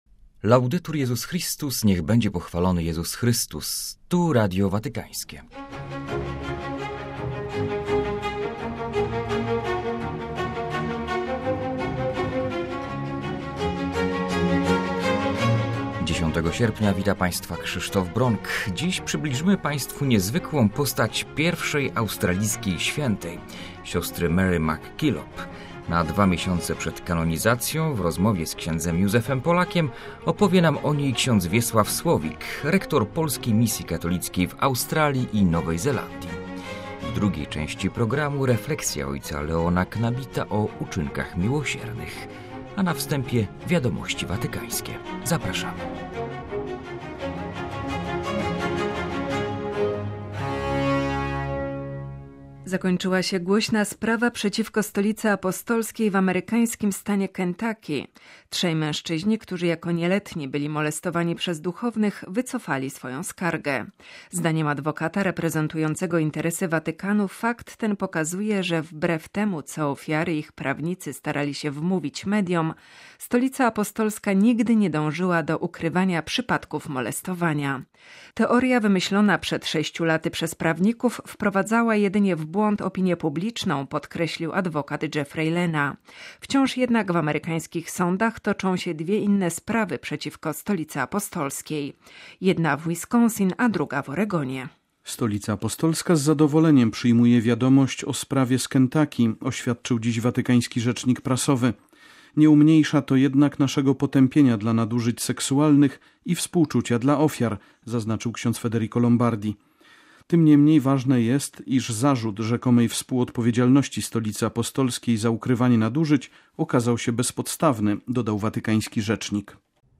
na wstępie wiadomości watykańskie.